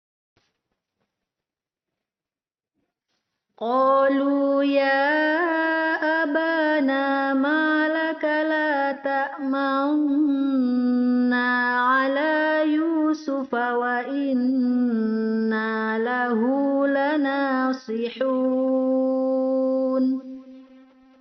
Isymam yaitu menampakkan Dhommah terbuang. Huruf Isymam hanya pada surat Yusuf ayat 11, isyarat bibir saja dimonyongkan seolah-olah kita baca manuna tapi suara yang terdengar tetap manna.